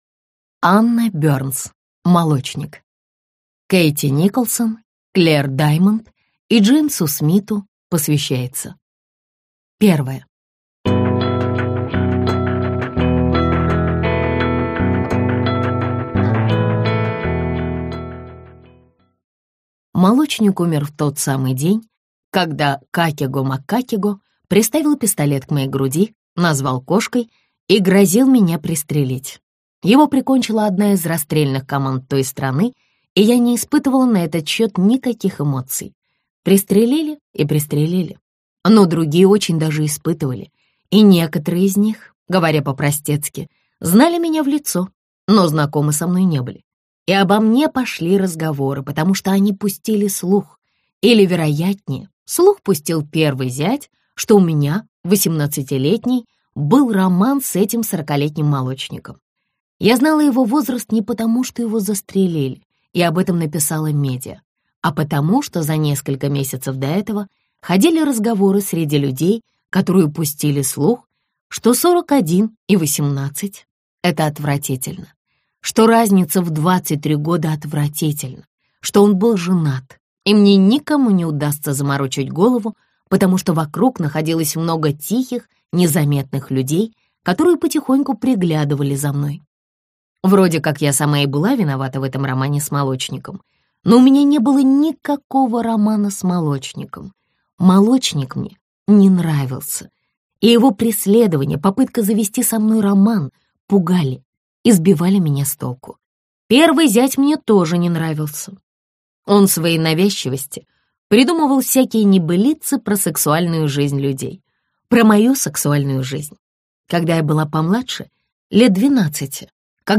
Прослушать фрагмент аудиокниги Молочник Анна Бёрнс Произведений: 1 Скачать бесплатно книгу Скачать в MP3 Вы скачиваете фрагмент книги, предоставленный издательством